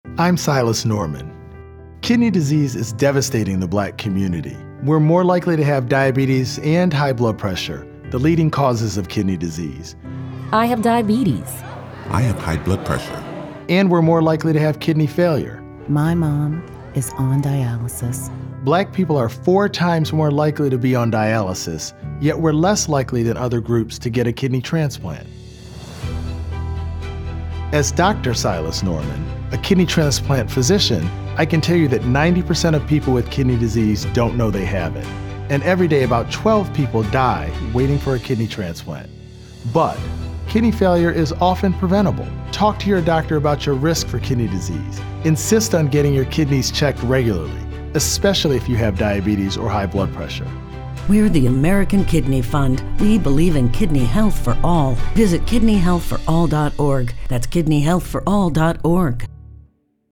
Broadcast-quality PSAs available at no cost to your station.